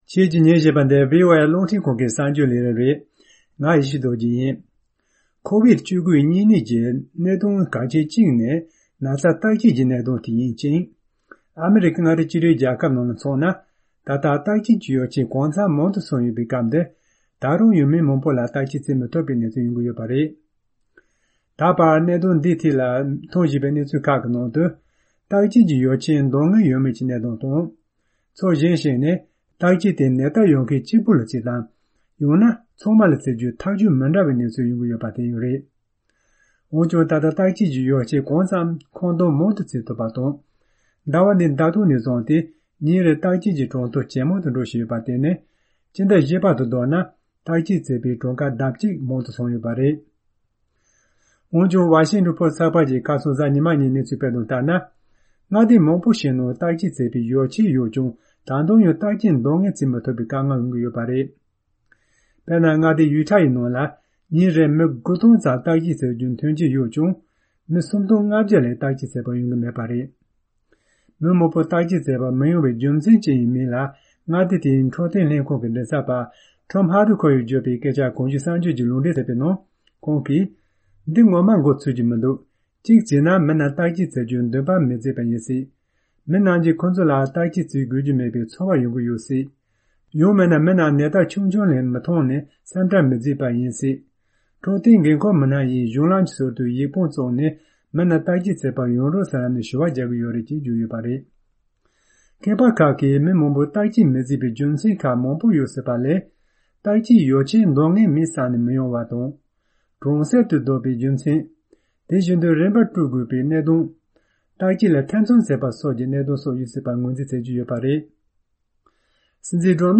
སྙན་སྒྲོན་ཞུ་གནང་གི་རེད།།